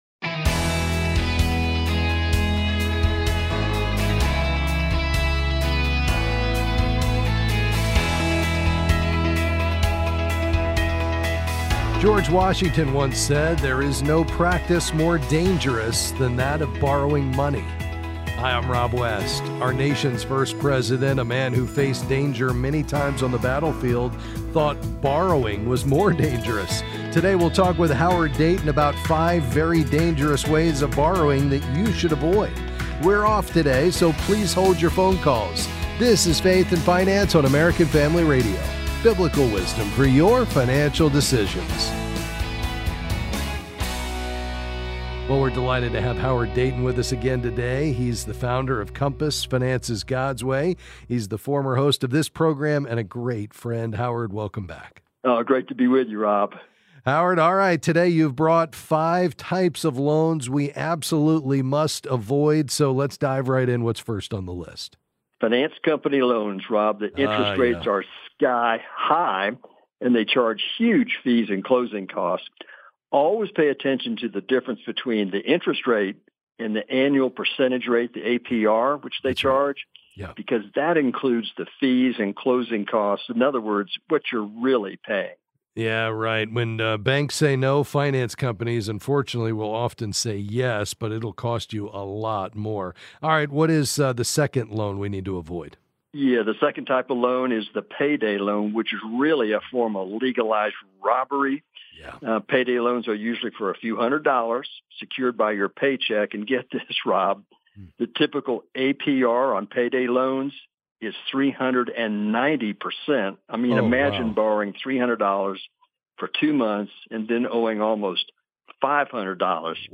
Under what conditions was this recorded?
Then, he’ll answer your calls on various financial topics.